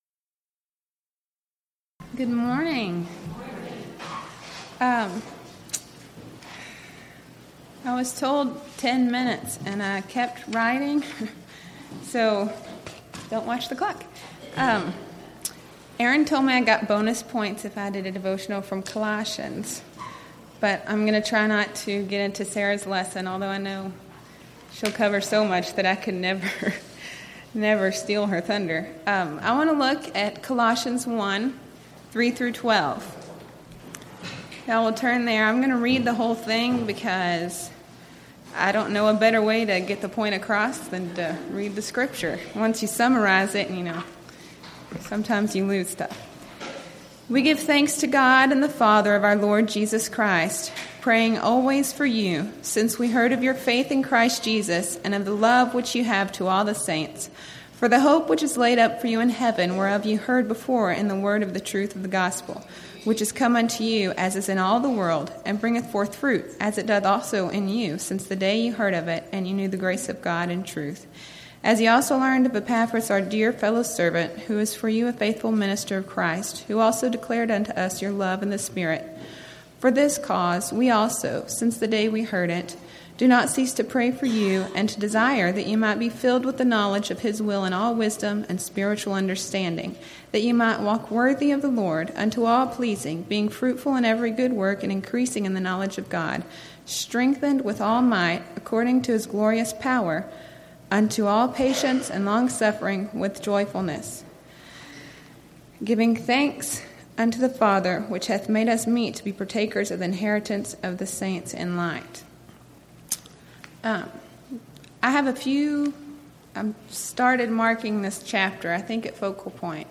Title: Devotional 2
Event: 2014 Texas Ladies in Christ Retreat Theme/Title: Colossians & Philemon